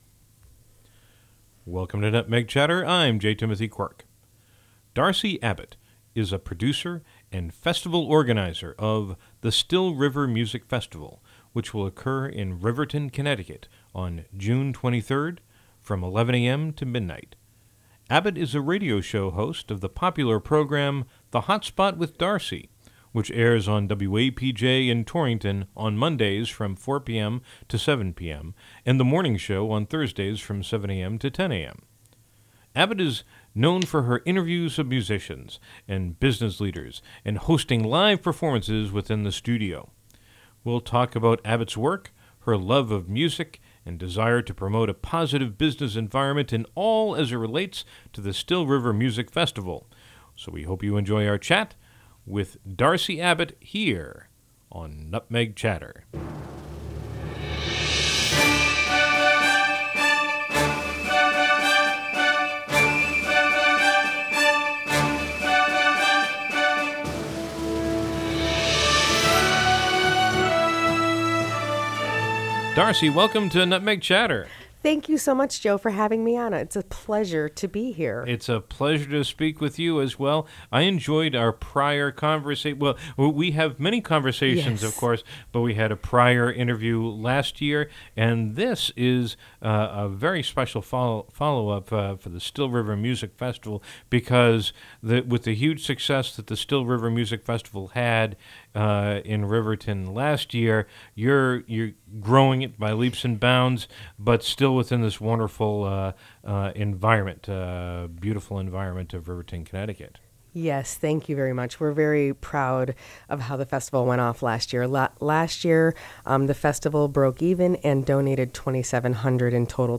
We’ll discuss her work and so much more here on Nutmeg Chatter. Bumper music